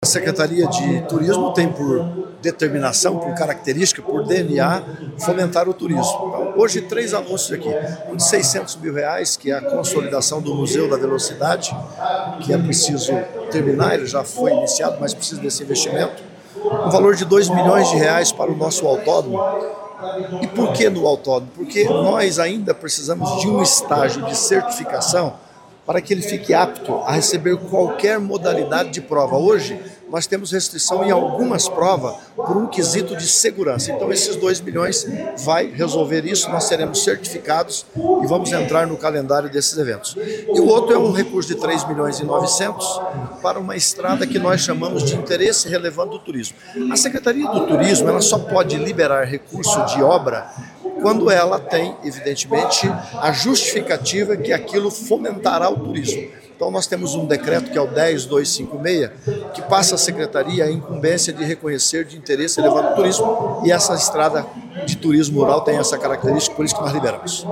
Sonora do secretário do Turismo, Leonaldo Paranhos, sobre a liberação de R$ 12,5 milhões para Cascavel